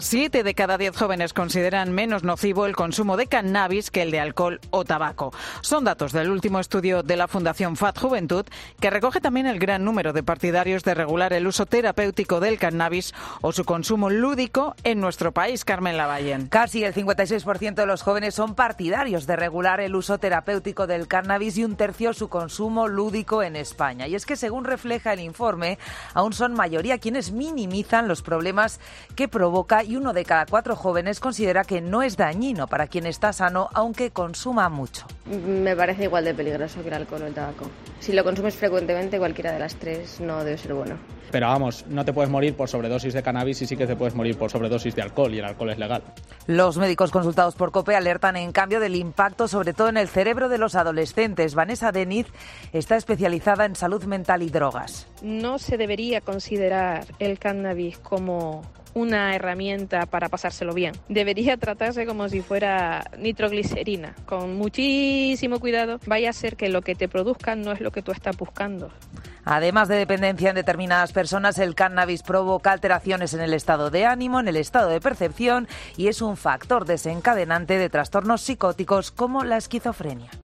"Me parece igual de peligroso que el alcohol y el tabaco, si lo consumes frecuentemente cualquiera de las tres no pueden ser buenas", dice una jóven preguntada por COPE que contrasta con lo que dice otro "no te puedes morir por sobredosis de cannabis y sí te puedes morir por sobredosis de alcohol y el alcohol es legal".